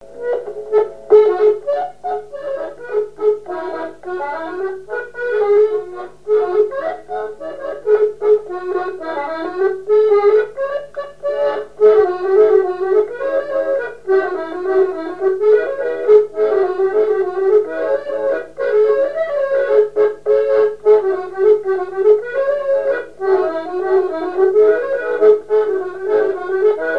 Saint-Michel-Mont-Mercure
danse : scottich trois pas
Pièce musicale inédite